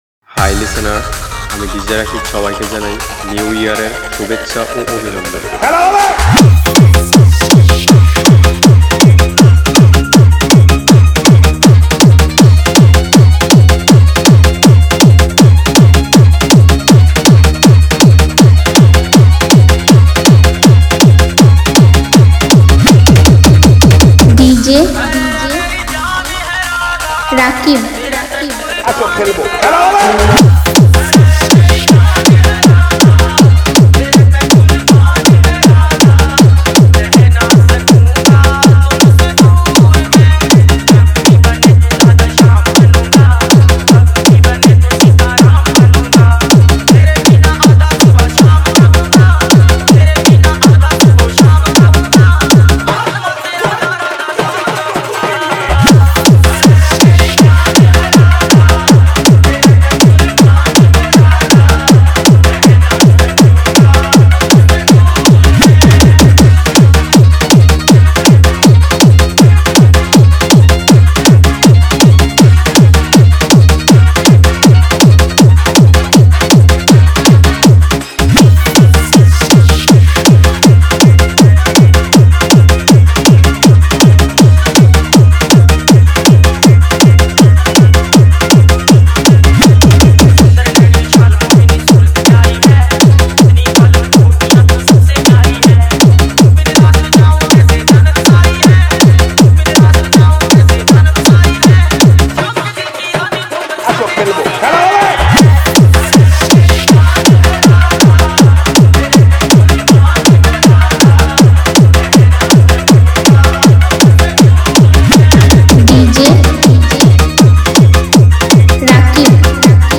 Category : Festival Remix Song